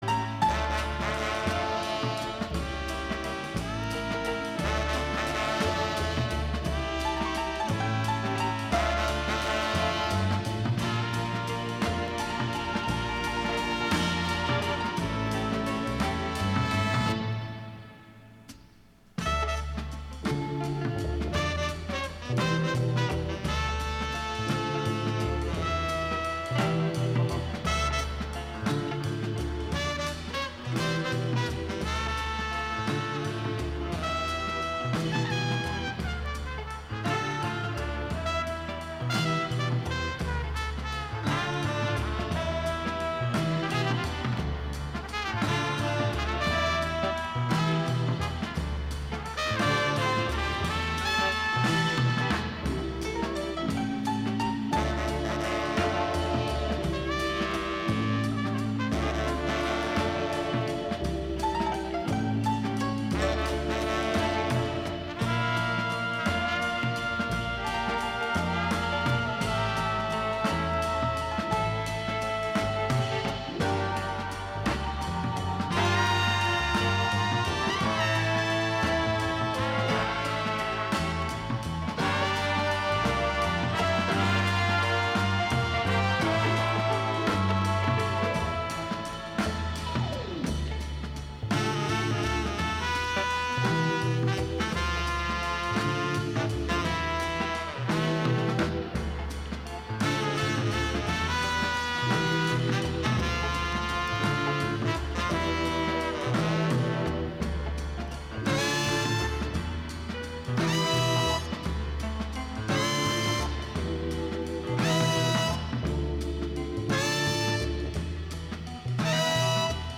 Sweet ska and rocksteady from the sixties, the deepest roots reggae from the seventies and the best dancehall from the eighties and beyond.